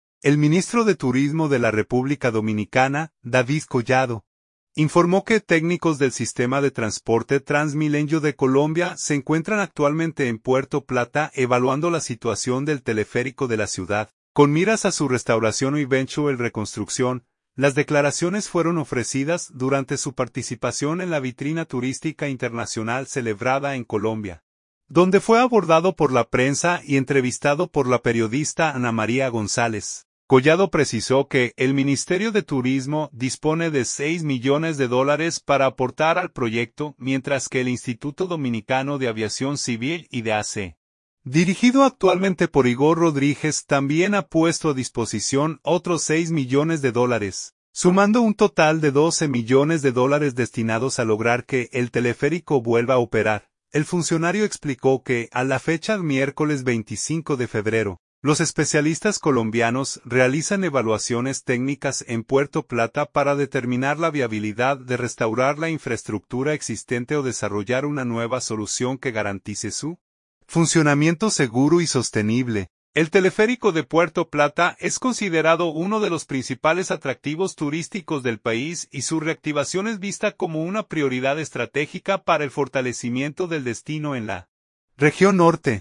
Las declaraciones fueron ofrecidas durante su participación en la vitrina turística internacional celebrada en Colombia, donde fue abordado por la prensa y entrevistado por la periodista